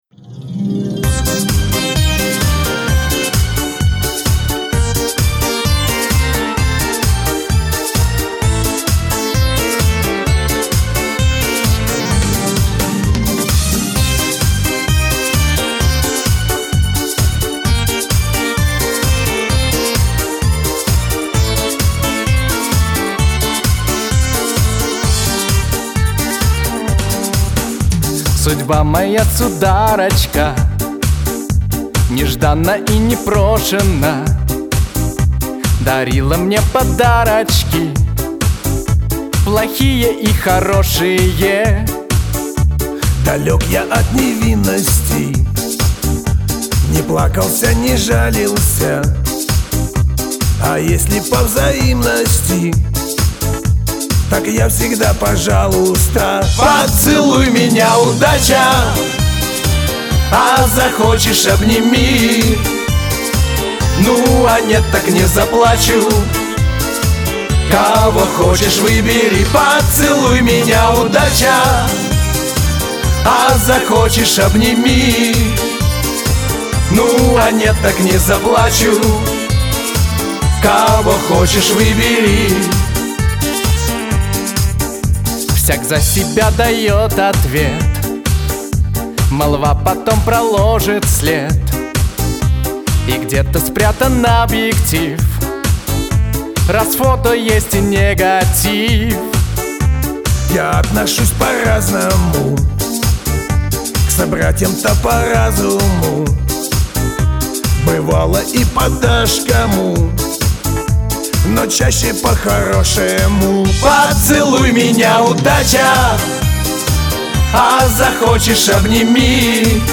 Песенка заводная ))))555